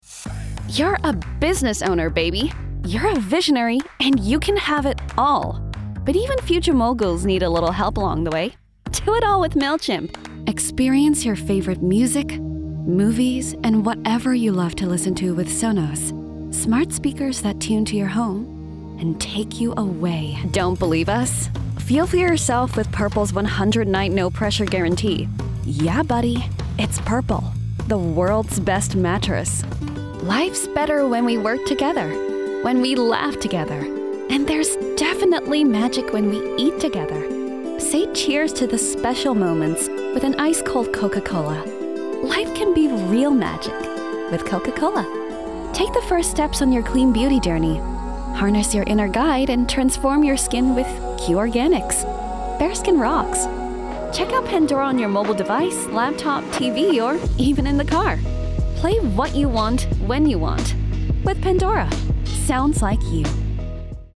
australian
commercial
conversational
friendly
smooth
warm